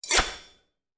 SWORD_09.wav